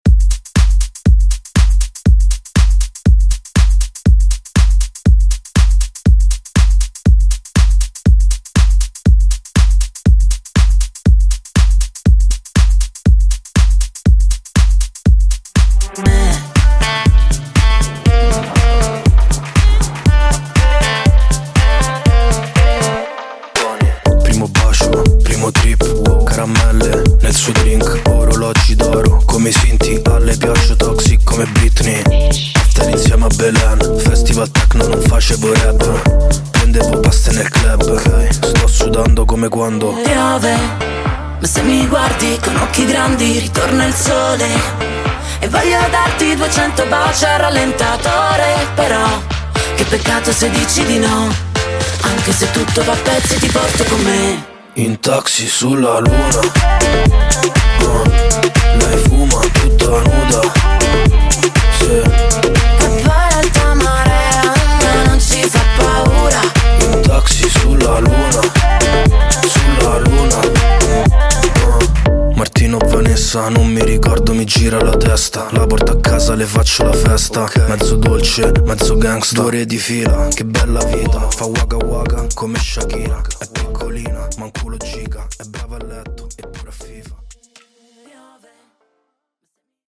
Genres: HIPHOP , RE-DRUM
Clean BPM: 105 Time